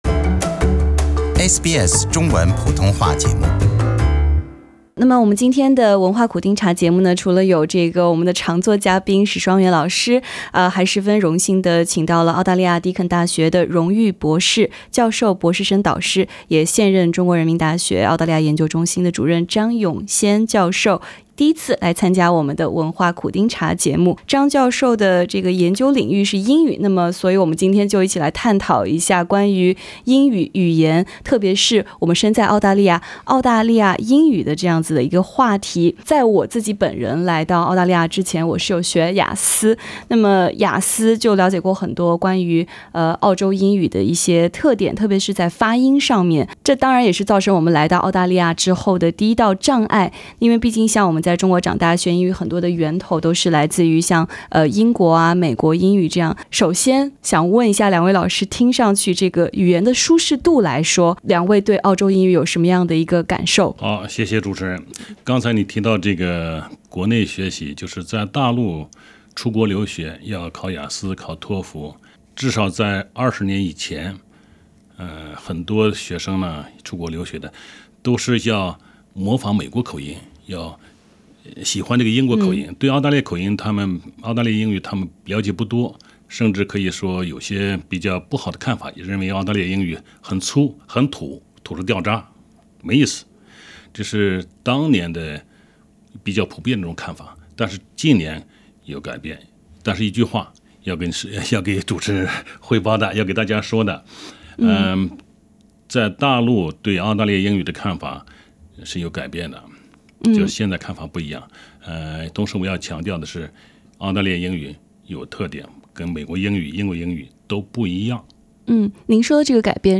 欢迎收听SBS 文化时评栏目《文化苦丁茶》，今天的话题是：澳洲英语的魅力（第1集）- 有“阶级”之分的英语。